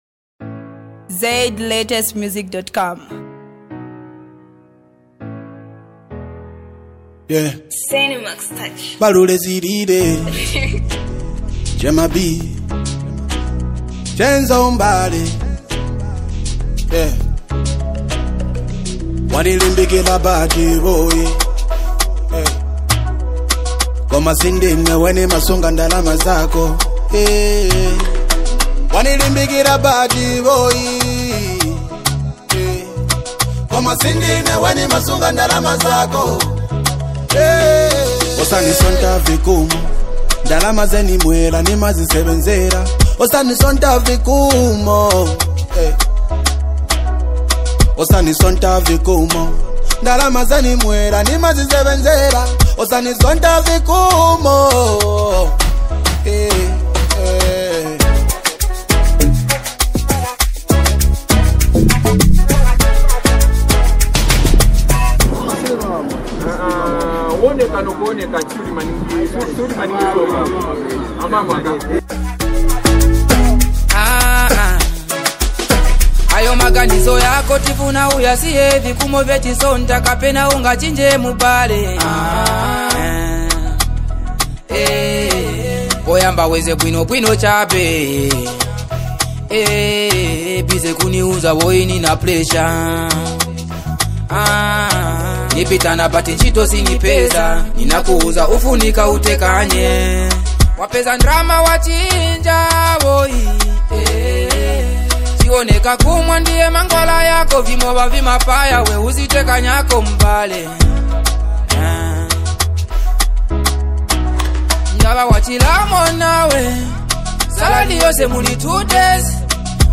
Zambian Music 2025
is a soulful and emotionally engaging song
delivers a sincere and expressive performance
signature soulful vocals
With its clean production
and beautiful harmony